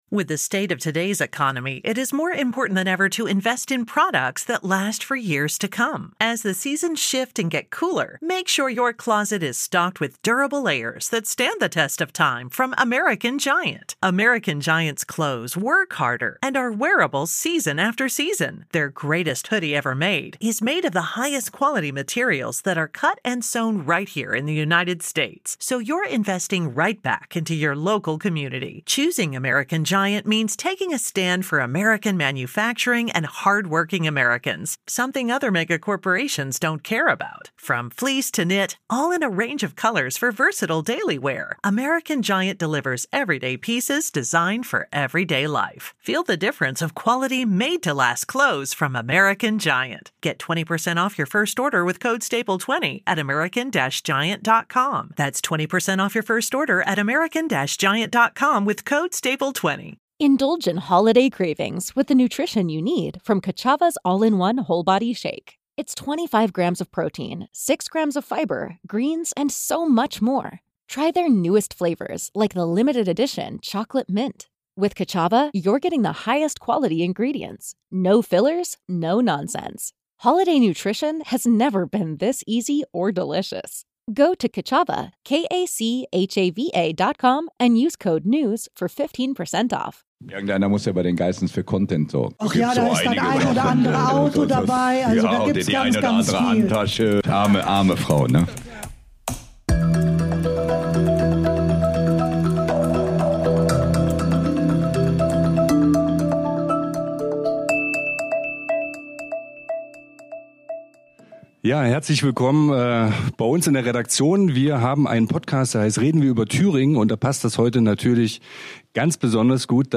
Deutschlands bekannteste Millionärsfamilie tourte erstmalig durch Thüringen. Zwischen Golfressort und Anna-Amalia-Bibliothek war Halt in unserer Redaktion für einen Plausch.